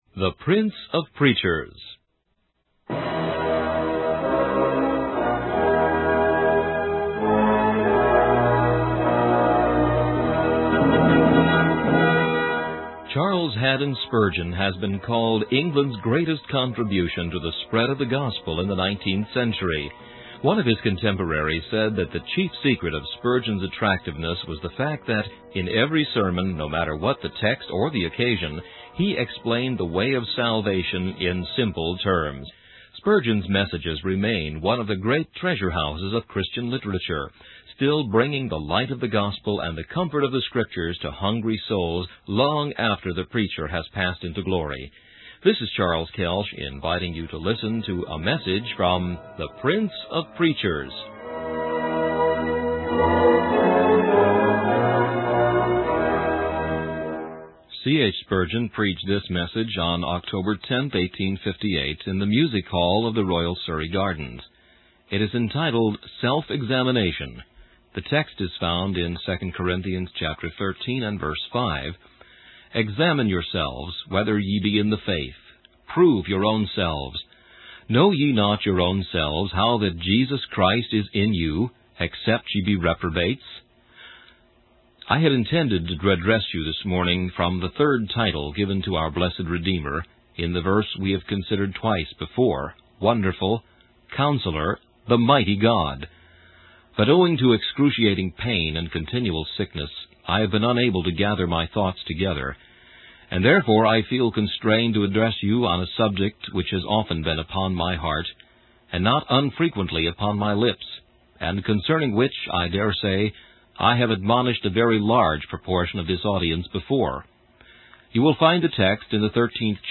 In this sermon, the preacher emphasizes the importance of self-examination. He compares it to a captain inspecting his soldiers on Review Day, looking closely at every detail. The preacher warns that God will not judge based on appearances, but will purify each individual through testing and scrutiny.